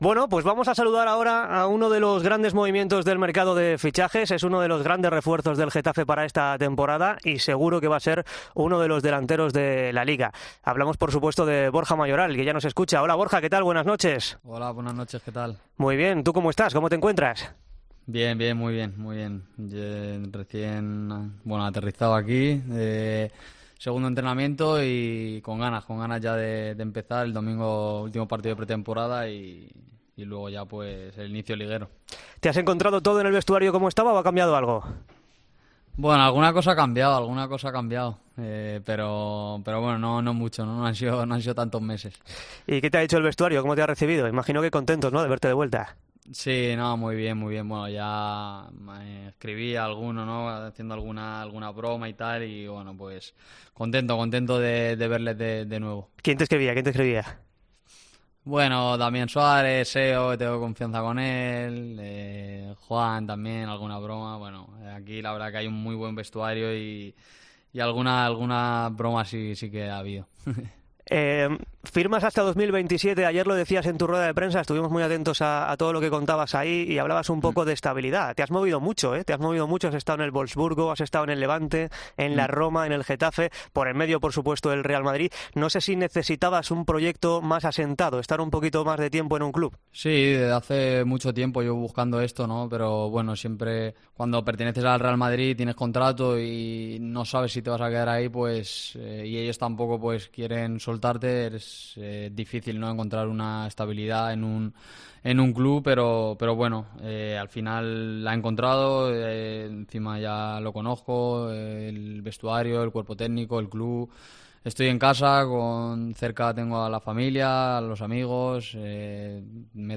Borja Mayoral, el reciente fichaje del Getafe, se ha quedado fuera de los planes de Ancelotti para la próxima temporada y analiza en los micrófonos de 'El Partidazo de COPE' su salida del Real Madrid.